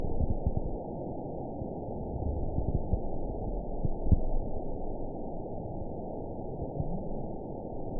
event 920564 date 03/30/24 time 03:35:14 GMT (1 year, 8 months ago) score 8.62 location TSS-AB03 detected by nrw target species NRW annotations +NRW Spectrogram: Frequency (kHz) vs. Time (s) audio not available .wav